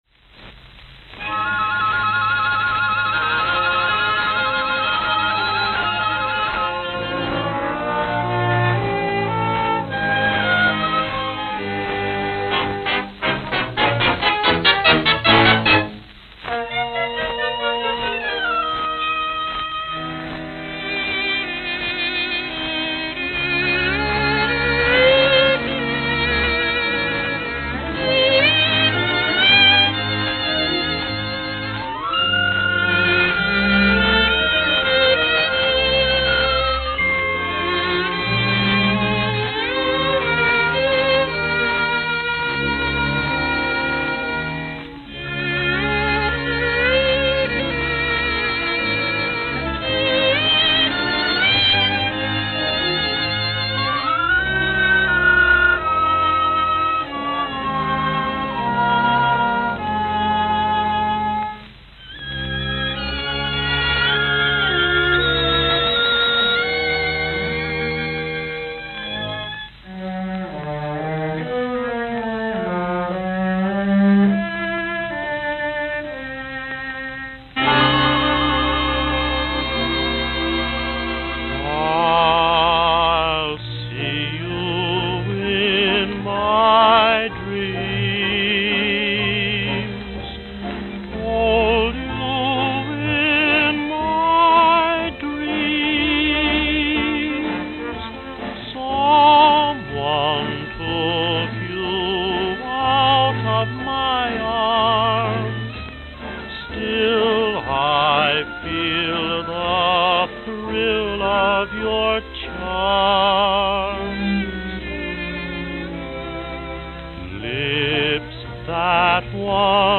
New York, New York New York, New York